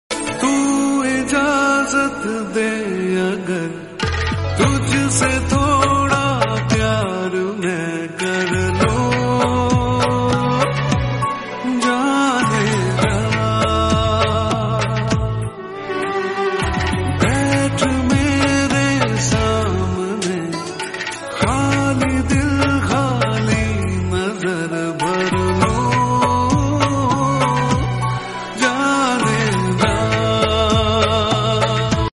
A beautiful romantic love ringtone for mobile.